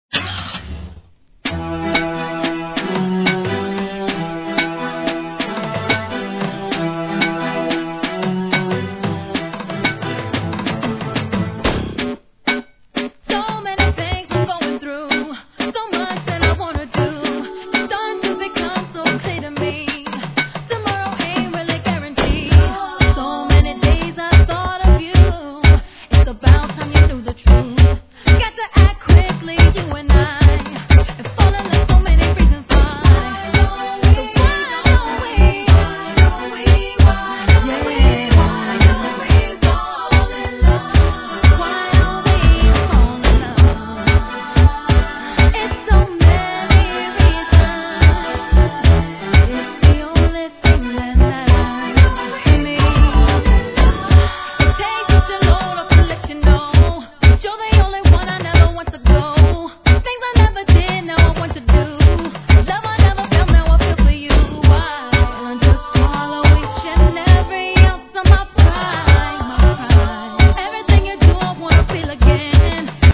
HIP HOP X REGGAE MUSH UP物!